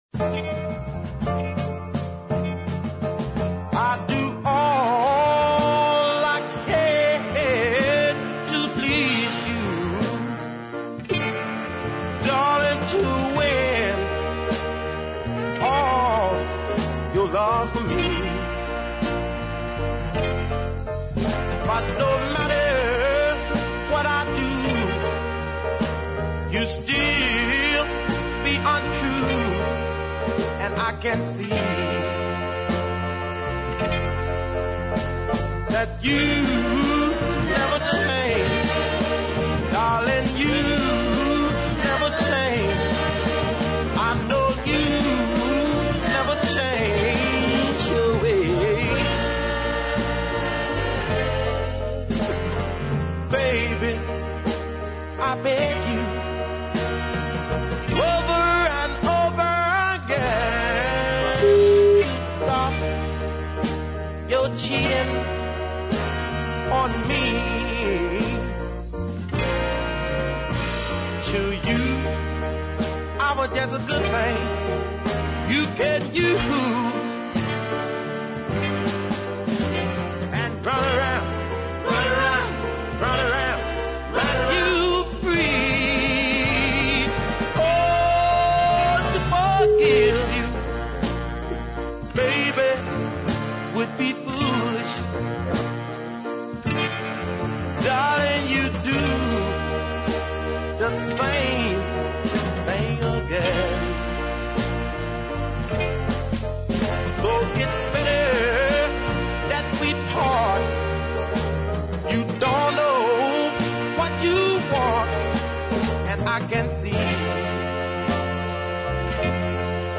a cracking deep soul number
testifying for all he’s worth on the top